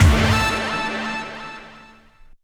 hitTTE68018stabhit-A.wav